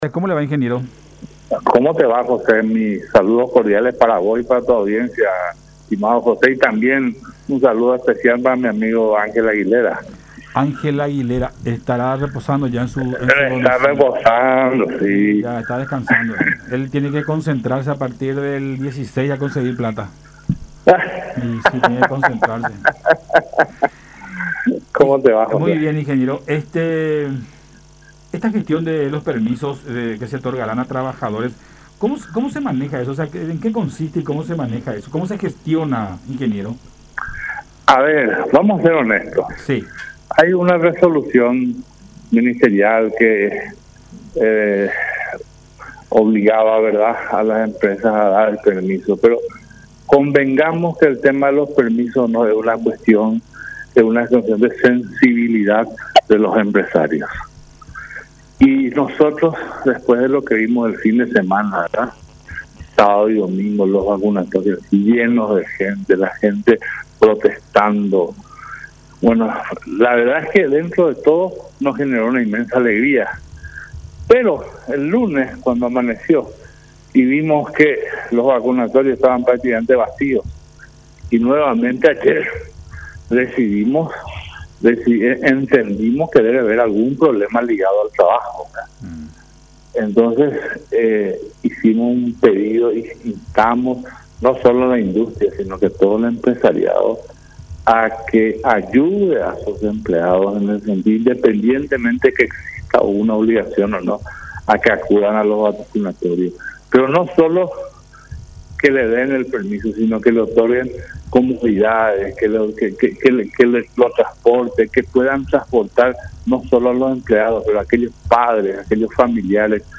en conversación con Buenas Tardes La Unión.